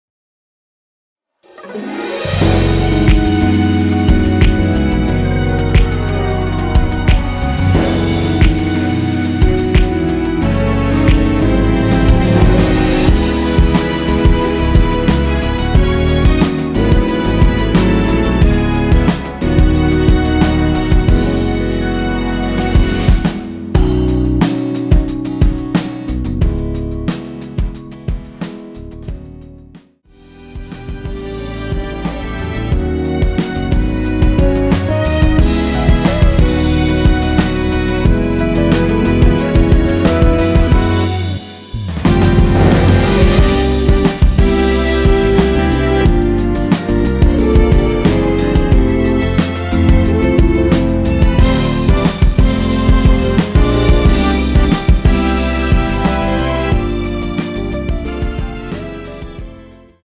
원키에서(-4)내린 MR입니다.
앞부분30초, 뒷부분30초씩 편집해서 올려 드리고 있습니다.
중간에 음이 끈어지고 다시 나오는 이유는